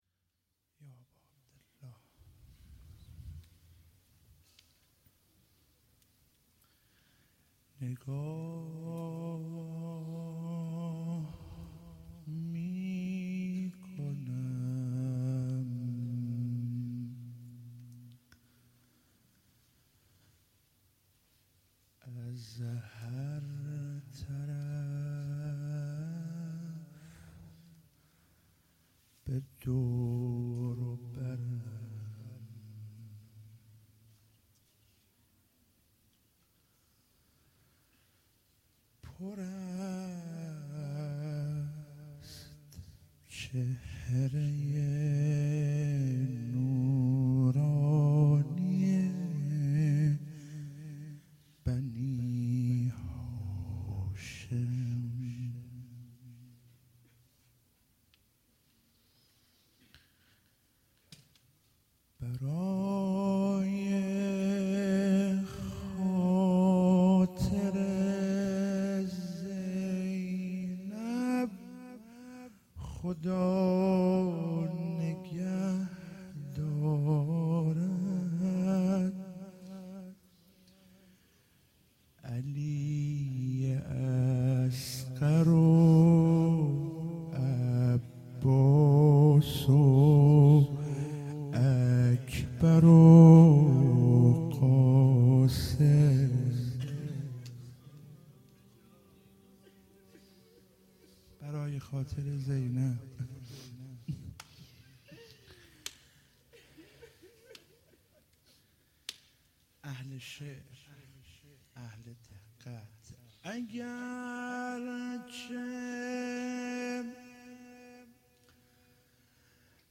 شب دوم محرم97 - روضه - نگاه میکنم از هر طرف